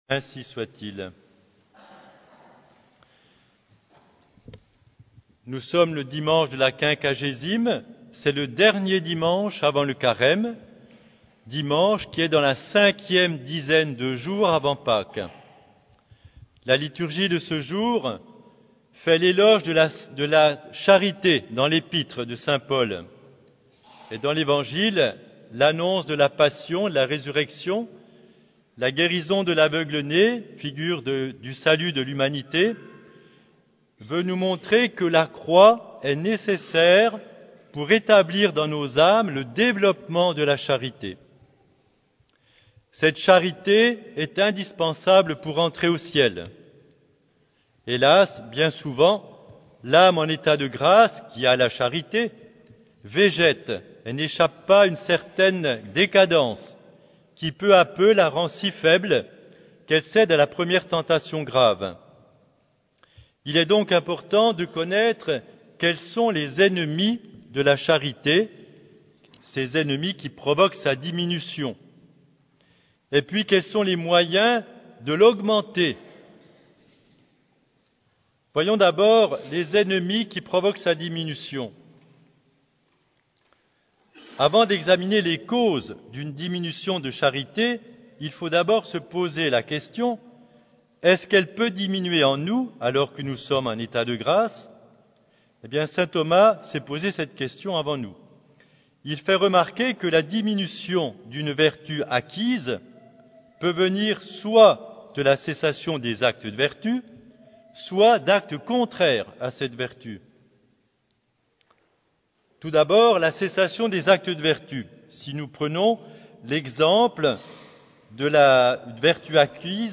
Occasion: Dimanche de la Quinquagésime
Type: Sermons